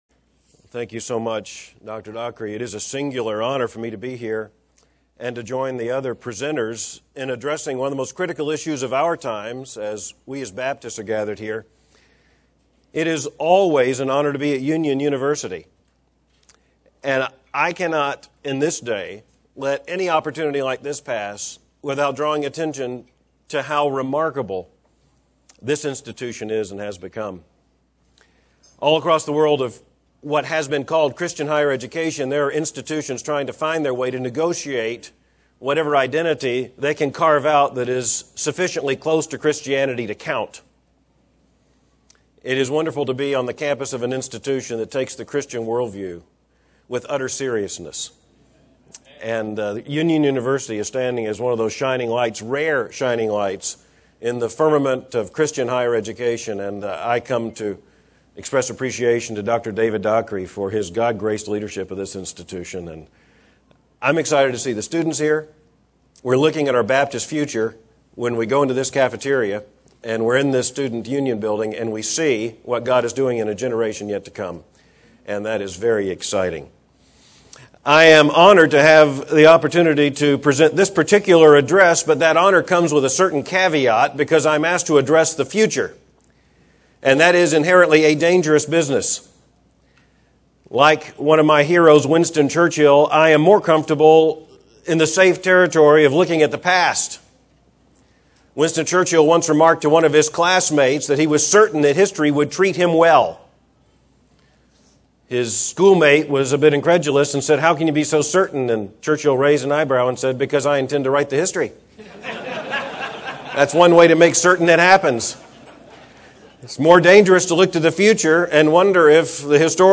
Baptist Identity Conference: R. Albert Mohler